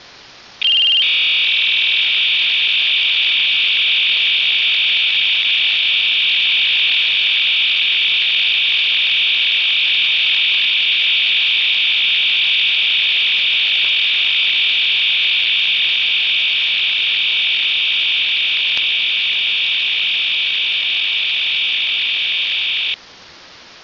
Сигнал на опознание